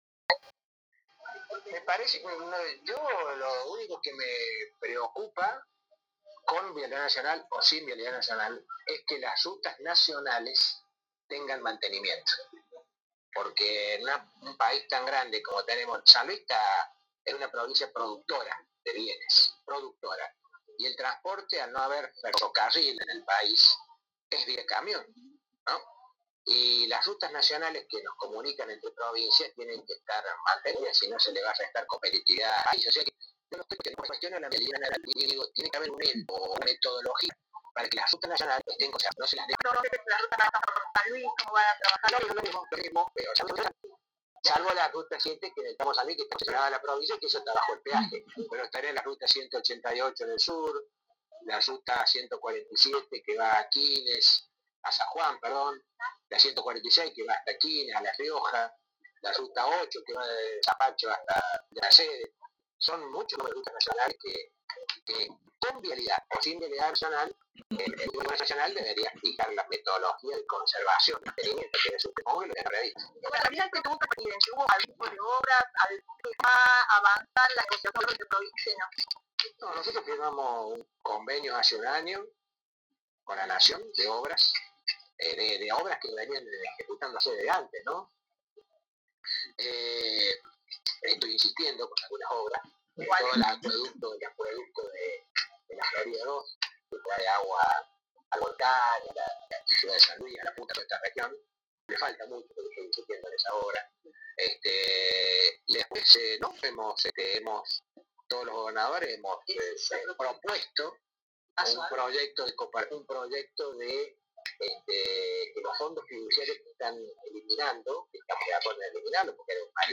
Esta mañana, en el marco de una actividad oficial, el Gobernador habló con los medios locales y dio su opinión acerca de las noticias nacionales sobre el decreto de disolución de organismos como Vialidad. También detalló cómo negocian los gobernadores con el Gobierno de Milei la continuidad de obras paralizadas.